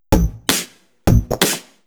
Index of /VEE/VEE2 Loops 128BPM
VEE2 Electro Loop 170.wav